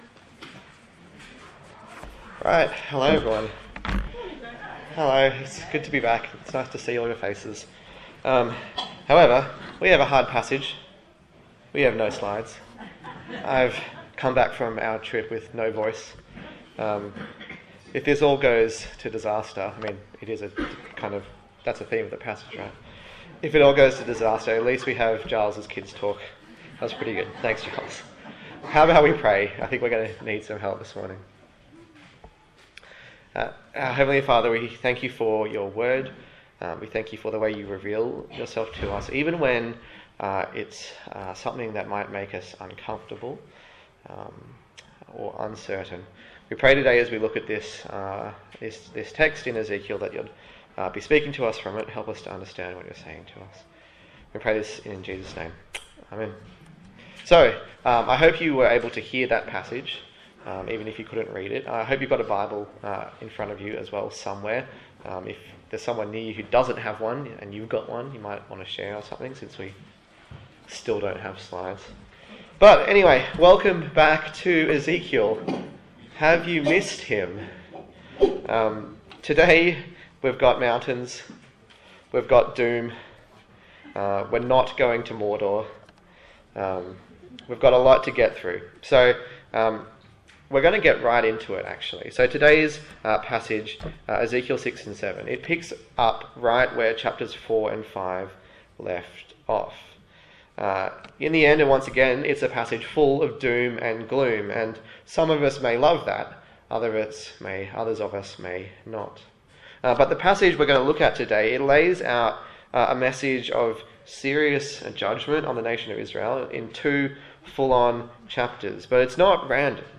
A sermon in the series on the book of Ezekiel
Service Type: Sunday Morning